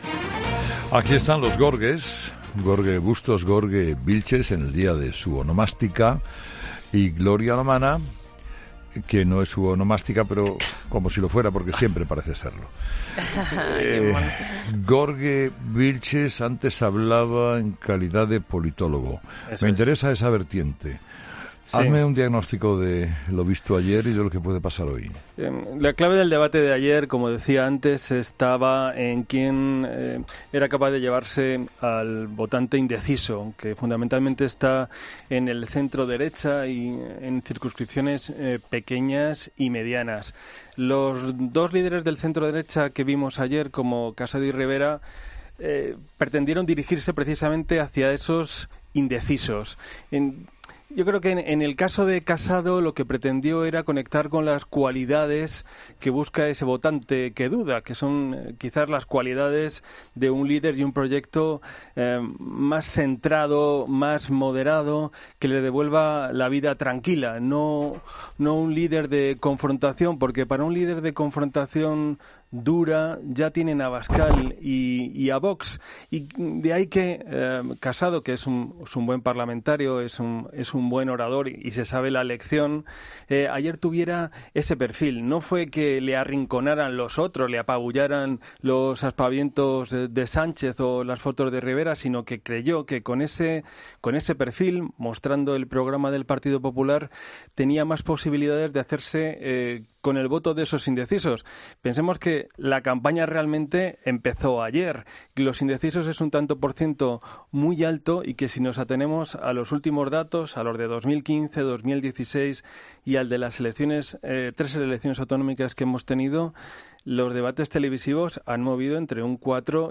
¿Qué opinan los tertulianos de 'Herrera en COPE' del debate?